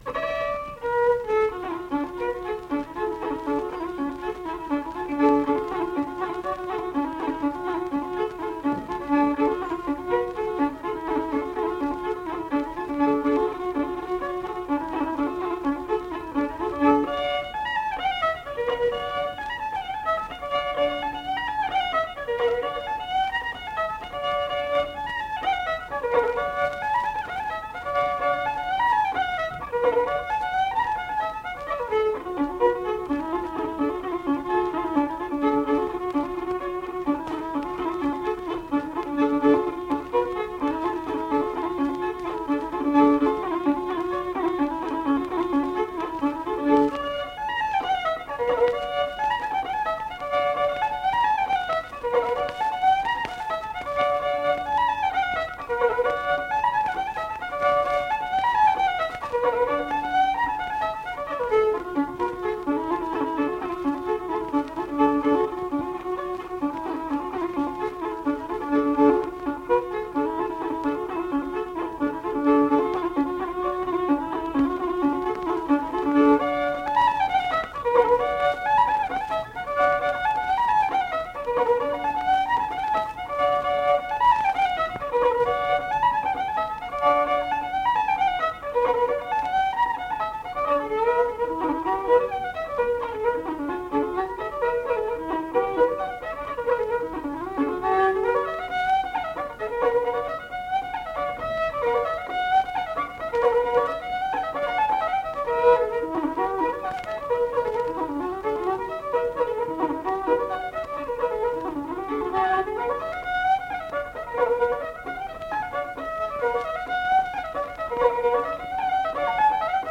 tears through two reels